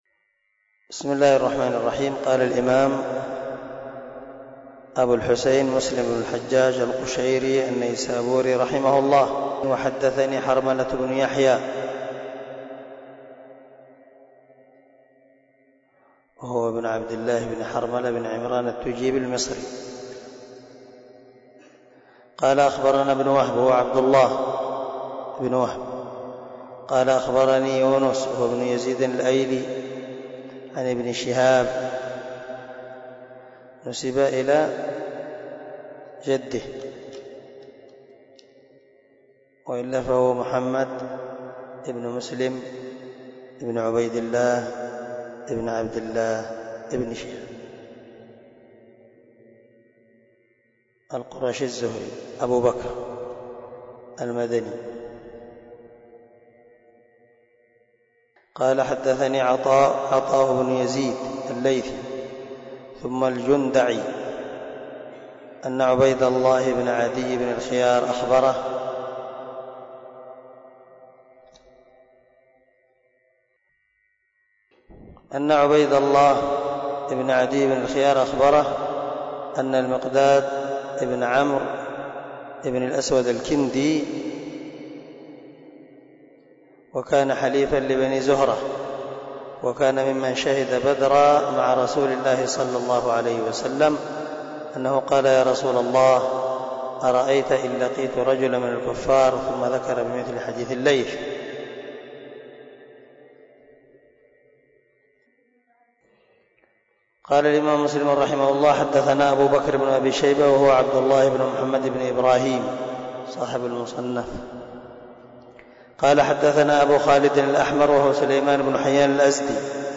سلسلة_الدروس_العلمية
دار الحديث- المَحاوِلة- الصبيحة.